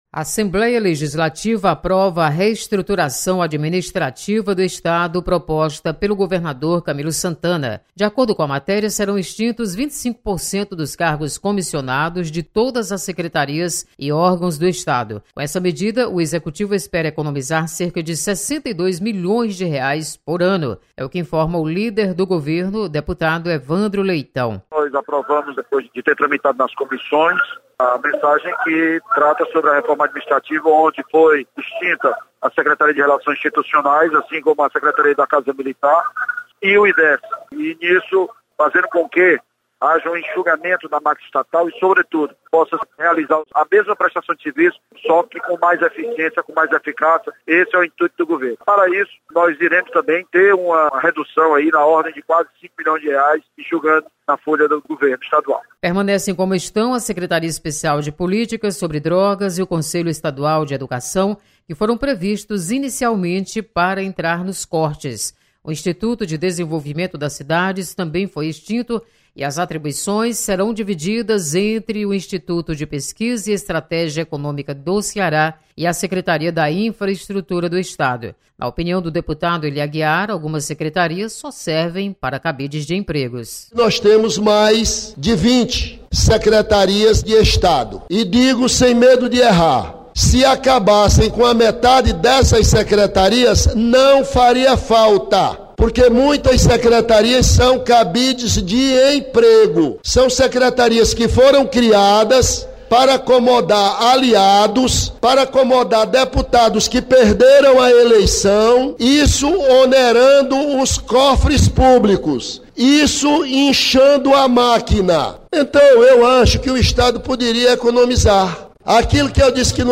Aprovada reestruturação administrativa do Estado. Repórter